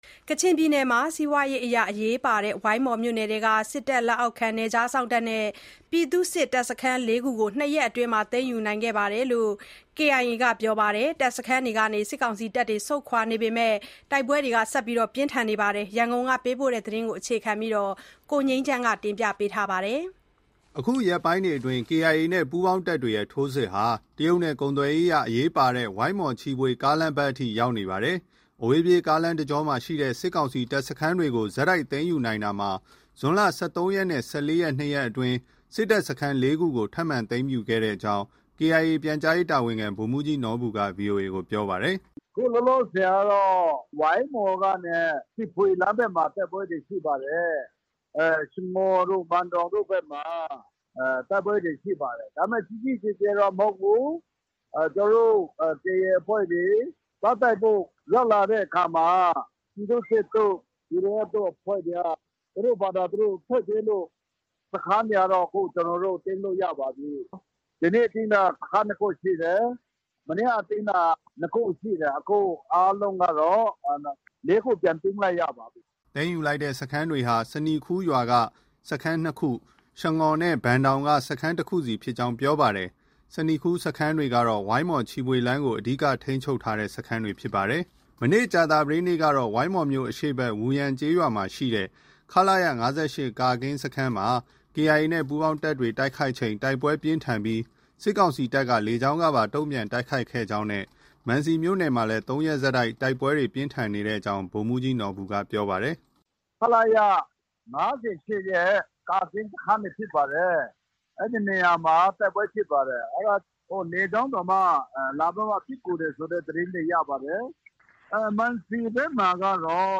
ကချင်ပြည်နယ်မှာ စီးပွားရေးအရအရေးပါတဲ့ ဝိုင်းမော်မြို့နယ်ထဲက စစ်တပ်လက်အောက်ခံ နယ်ခြားစောင့်နဲ့ ပြည်သူ့စစ် တပ်စခန်း ၄ ခုကို ၂ ရက်အတွင်း သိမ်းယူခဲ့တယ်လို့ KIA ကပြောပါတယ်။ တပ်စခန်းတွေကနေ စစ်တပ်တွေ ဆုတ်ခွာနေပေမဲ့ တိုက်ပွဲတွေ ပြင်းထန်နေတဲ့ အခြေအနေ ရန်ကုန်ကပေးပို့တဲ့သတင်းကို တင်ပြထားပါတယ်။